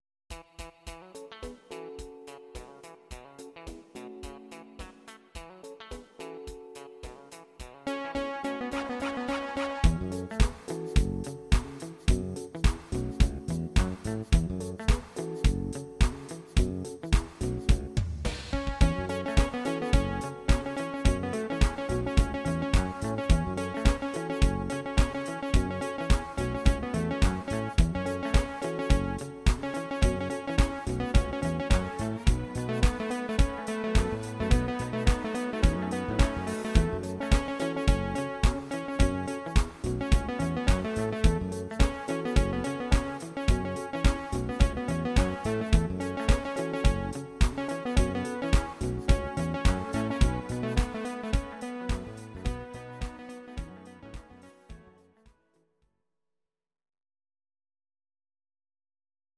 Audio Recordings based on Midi-files
Pop, Disco, 1990s